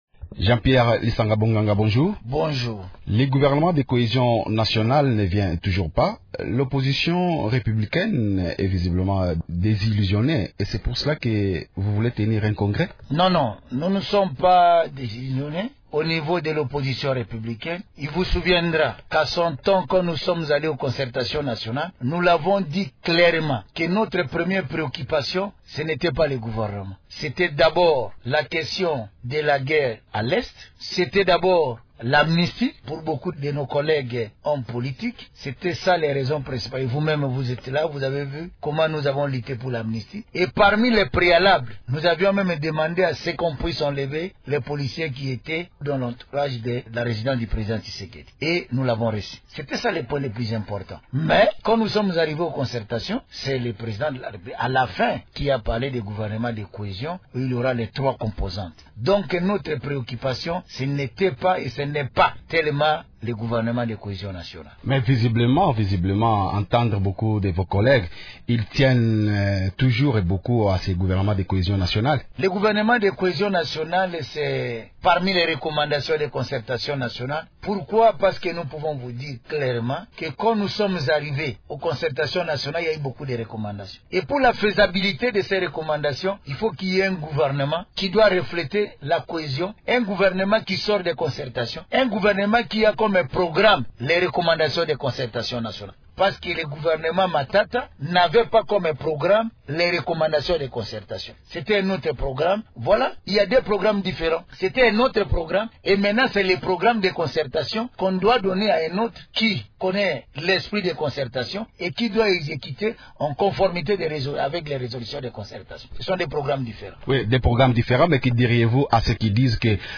L’attente, depuis huit mois, du gouvernement de cohésion nationale, annoncé par le chef de l’Etat après les concertations nationales, continue d’alimenter les débats dans les états-majors des partis politiques. Invité de Radio Okapi ce matin, le modérateur de l’Opposition républicaine, Jean-Pierre Lisanga Bonganga, a indiqué que la mise en place de ce gouvernement n’est pas la première préoccupation pour sa plate-forme mais ce gouvernement est nécessaire pour exécuter les recommandations des concertations nationales.